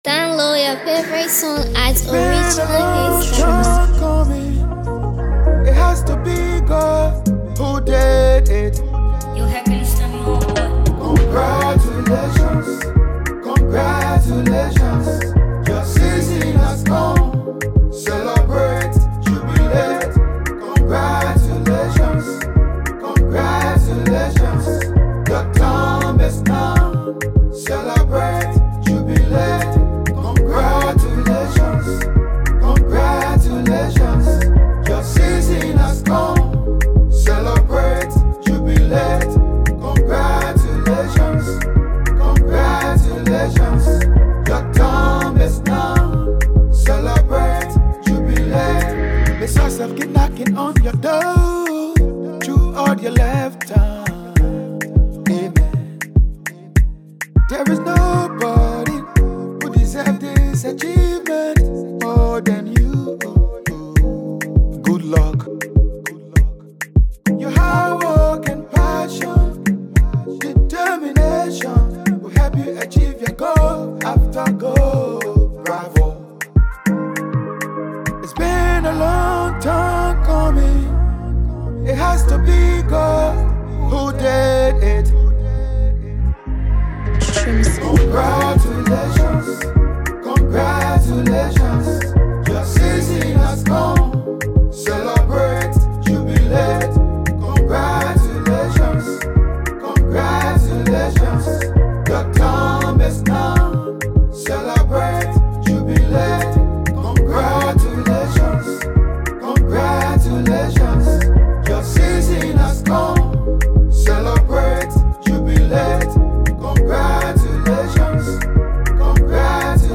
Inspirational Gospel Artist
a nice song for celebration
Gospel